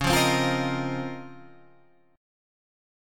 C#M13 chord